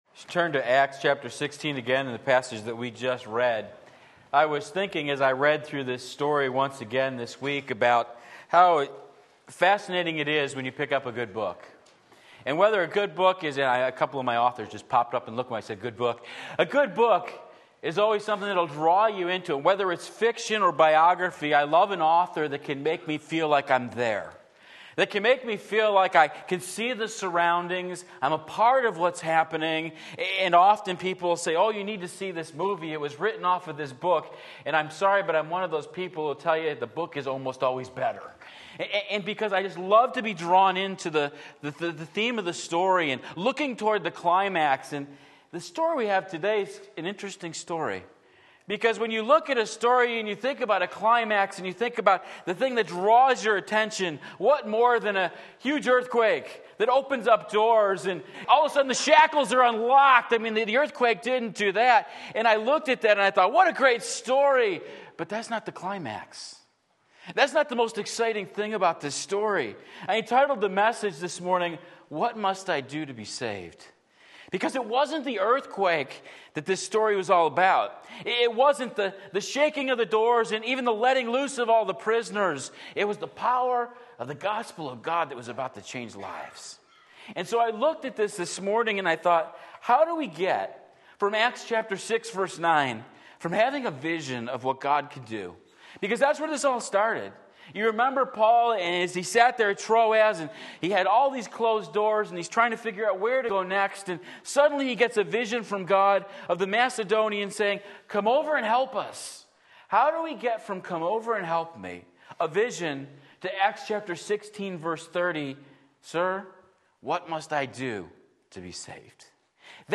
Sermon Link
Acts 16:11-40 Sunday Morning Service